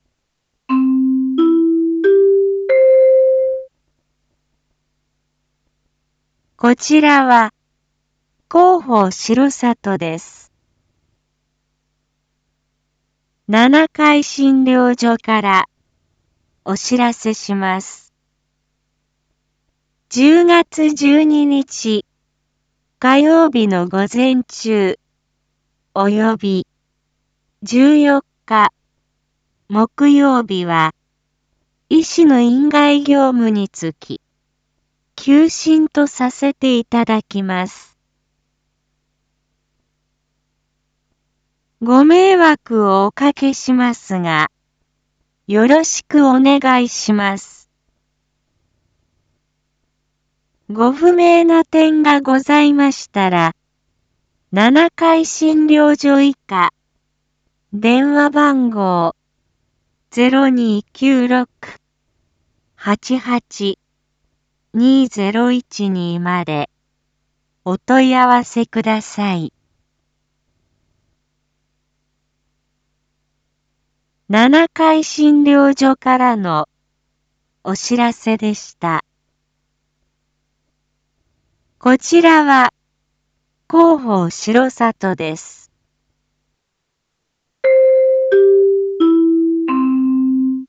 一般放送情報
Back Home 一般放送情報 音声放送 再生 一般放送情報 登録日時：2021-10-11 07:01:32 タイトル：R3.10.11 7時放送 インフォメーション：こちらは広報しろさとです 七会診療所からお知らせします １０月１２日火曜日の午前中および１４日木曜日は、医師の院外業務につき休診とさせていただきます。